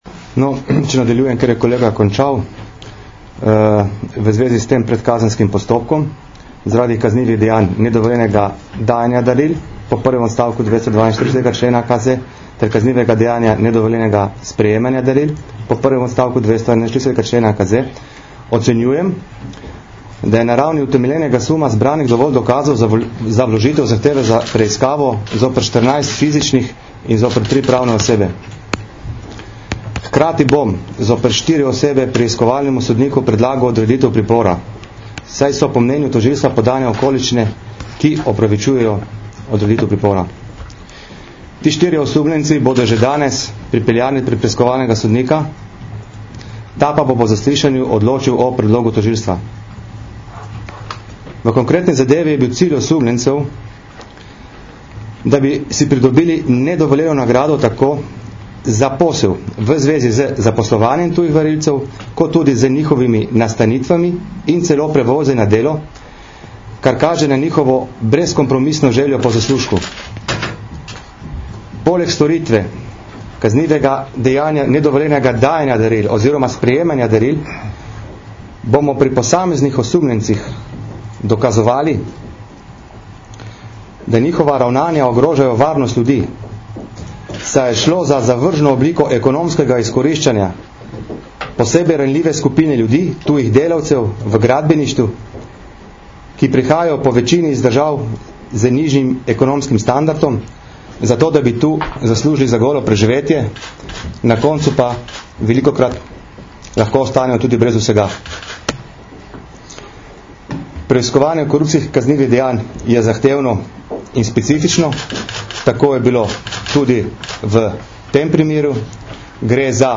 Izjavi sta danes, 1. marca 2013, dala vodja Specializiranega državnega tožilstva RS mag. Harij Furlan (levo) in vodja Nacionalnega preiskovalnega urada mag. Bruno Blažina.
Zvočni posnetek izjave mag. Harija Furlana (mp3)